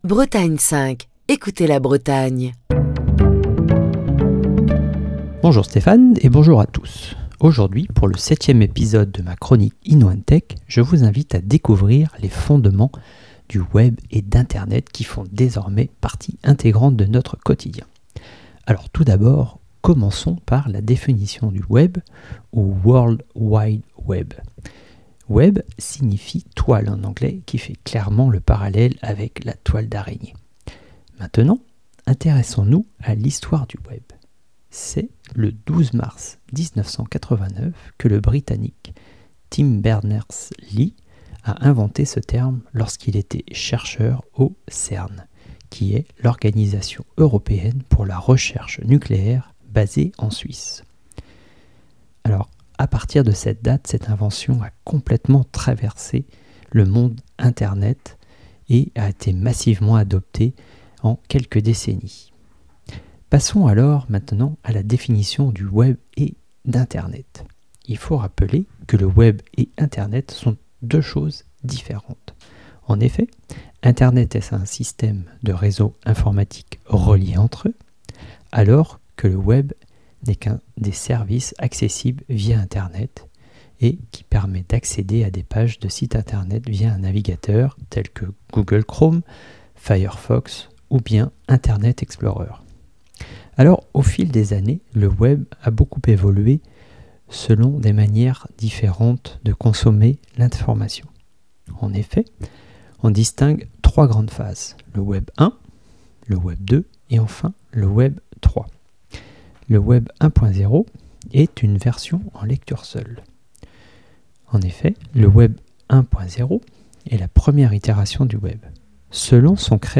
Chronique du 15 mars 2023.